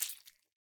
Footsteps
slime4.ogg